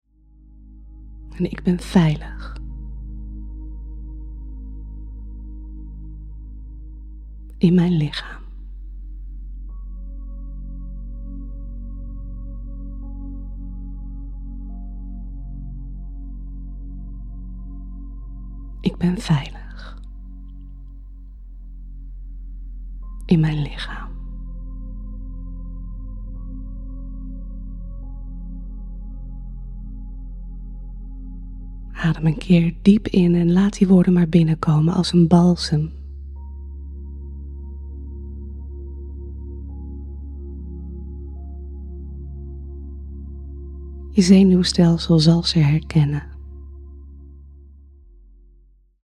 Meditatie "Rescue 911 - Eerste Hulp"